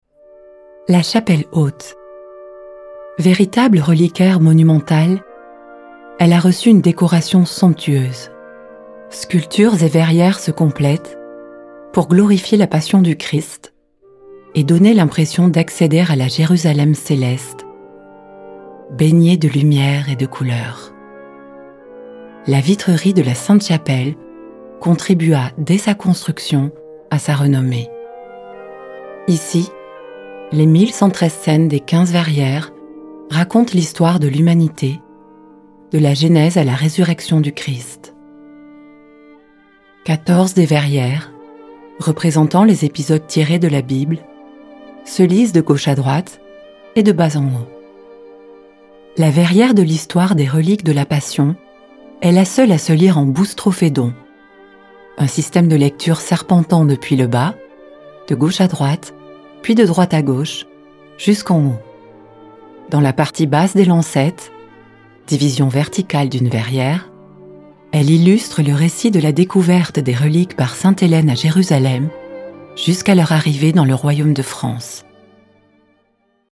Audioguide Sainte-Chapelle
7 - 50 ans - Mezzo-soprano